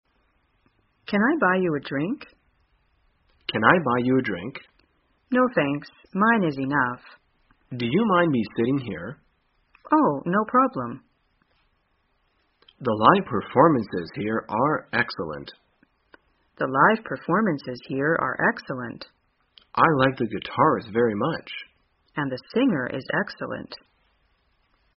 在线英语听力室生活口语天天说 第273期:怎样在酒吧闲谈的听力文件下载,《生活口语天天说》栏目将日常生活中最常用到的口语句型进行收集和重点讲解。真人发音配字幕帮助英语爱好者们练习听力并进行口语跟读。